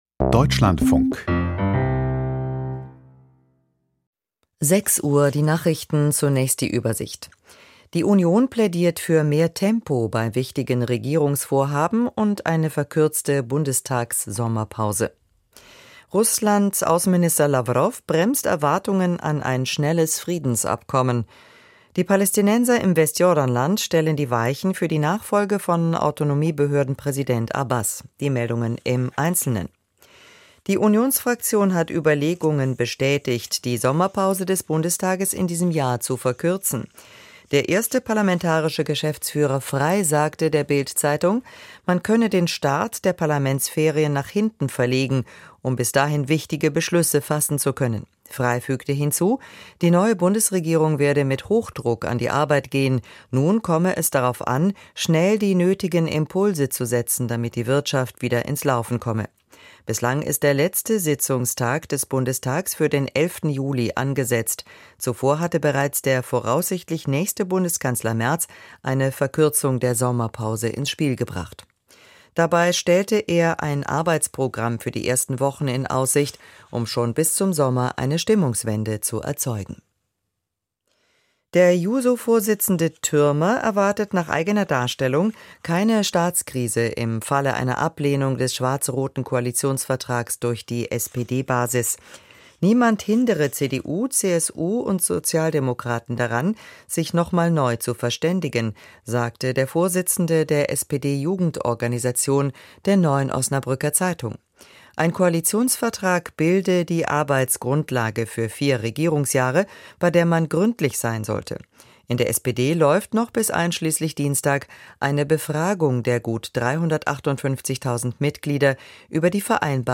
Die Deutschlandfunk-Nachrichten vom 25.04.2025, 06:00 Uhr